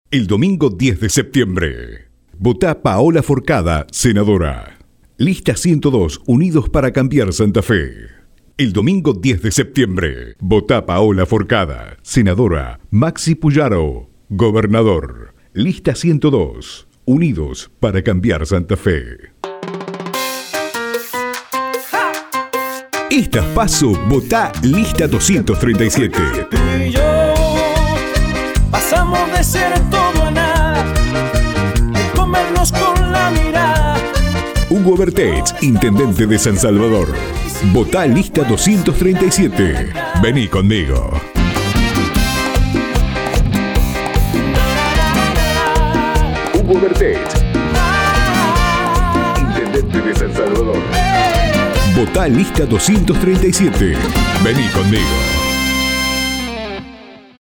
voice over
Demo-avance-Politico.mp3